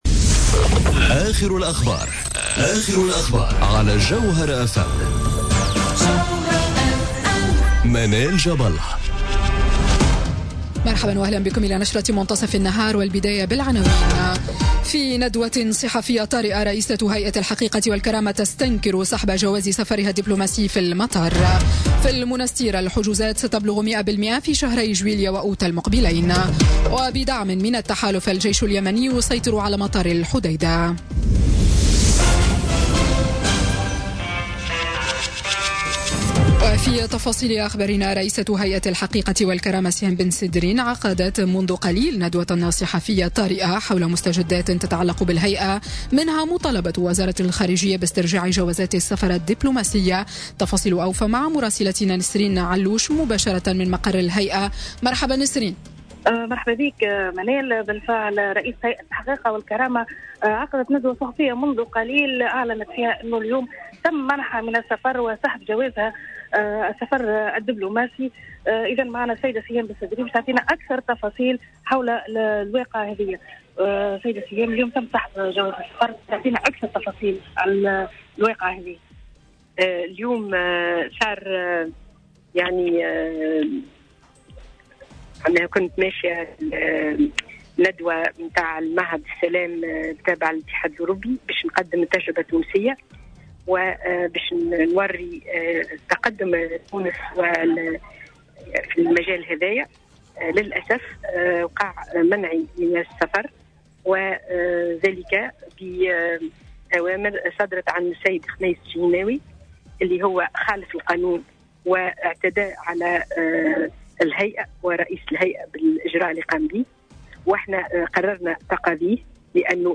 نشرة أخبار منتصف النهار ليوم الثلاثاء 19 جوان 2018